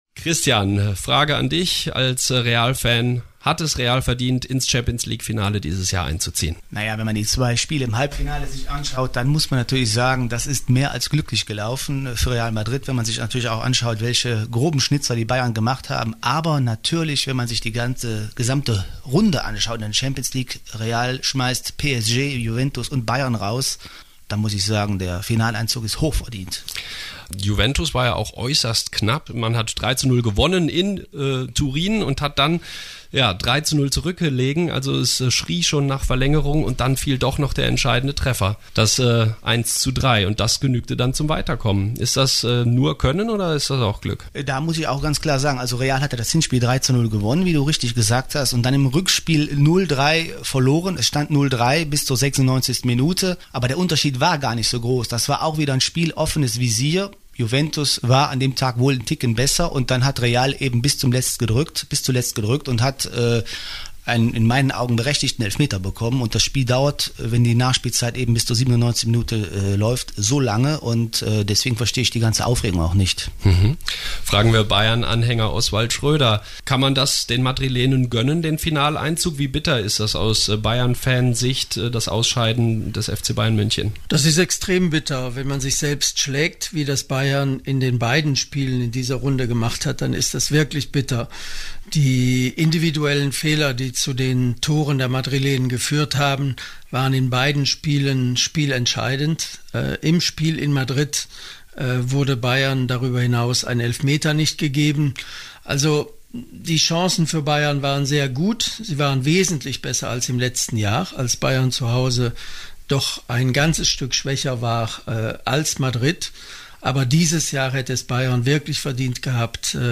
Alle drei Fußballfans im Studio tippten übrigens auf ein Weiterkommen Liverpools und sehen die Mannschaft von der Insel auch mit leichten Vorteilen für’s Endspiel gegen Madrid.